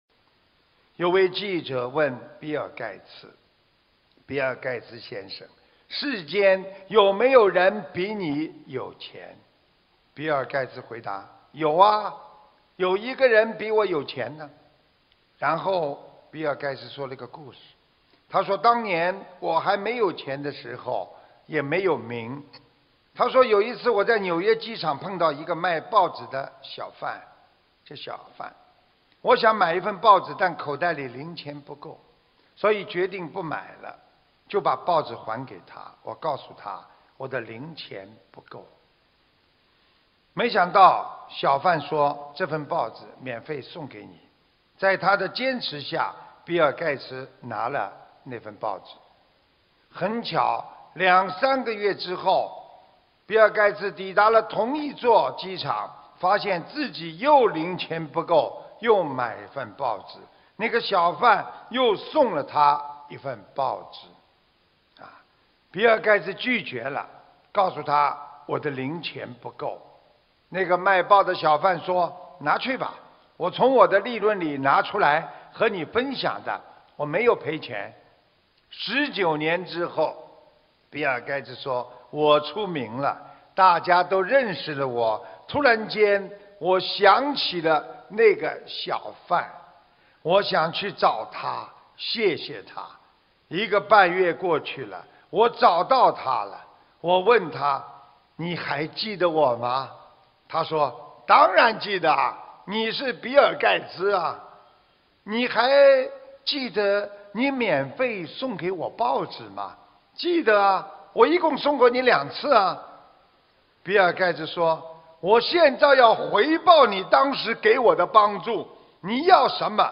法会开示